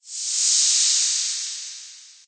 whoosh_long_2.ogg